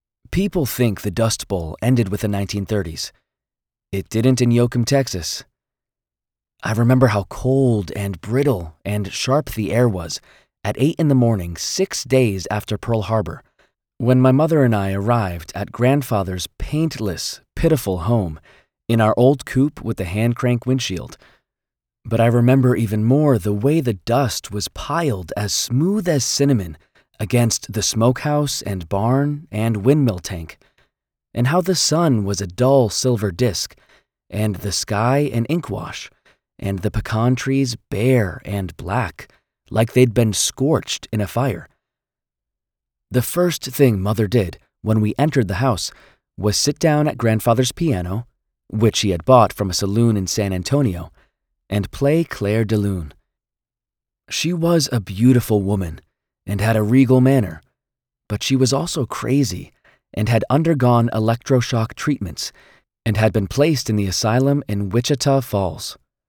Audiobook Demo